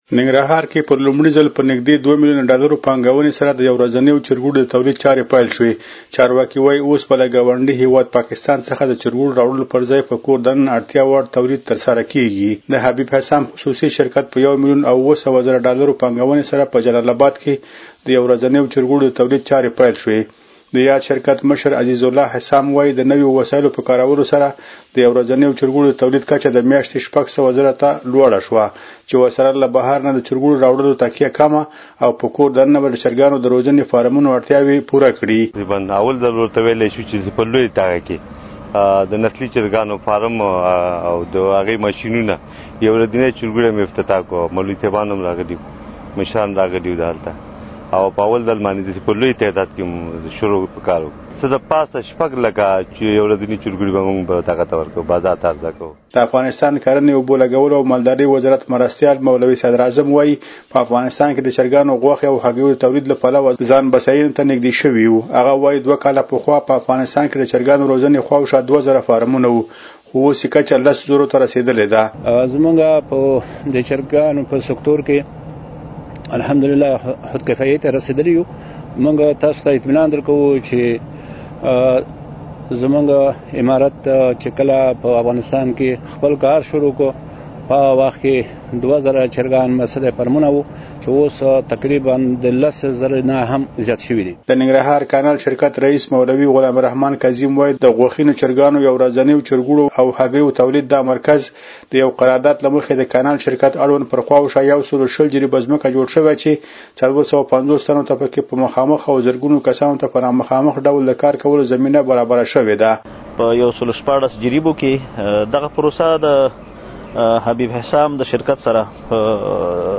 زمونږ خبریال راپور راکړی په ننګرهار کې په لومړي ځل په نږدې دوه ملیونه ډالرو پانګونې سره د یو ورځنیو چرګوړو د تولید چارې پېل شوې.